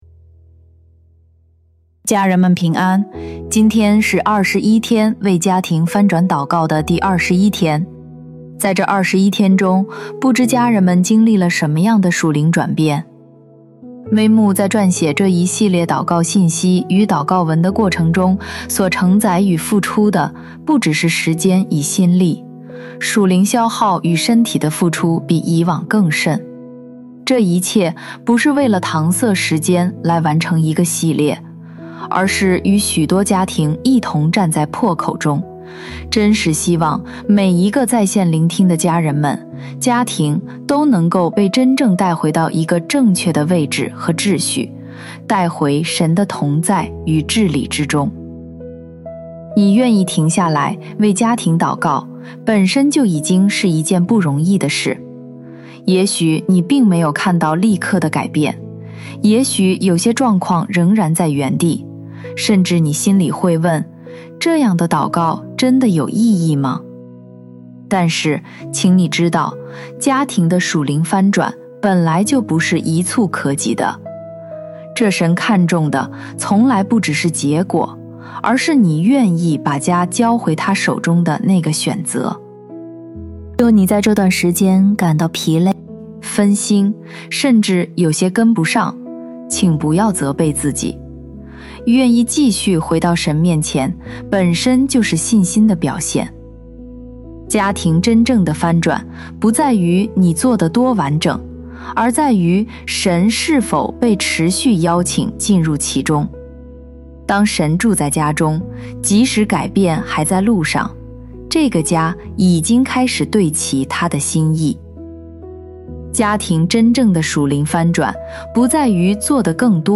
本篇由微牧之歌撰文及祷告 21天为翻转家庭祷告 第 21 天｜家被翻转，城市就开始被照亮 第 21 天音频 &…